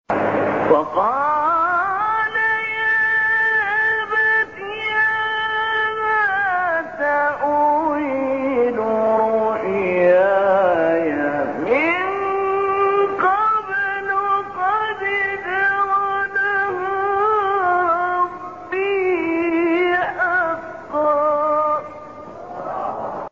به گزارش خبرگزاری بین المللی قرآن(ایکنا)، شش فراز صوتی با صوت محمود علی البناء، قاری برجسته مصری در کانال تلگرامی قاریان مصری منتشر شده است.
این مقاطع صوتی از تلاوت سوره یوسف بوده است که در مقام‌های بیات، صبا، نهاوند، رست و سه گاه اجرا شده‌اند.
مقام رست2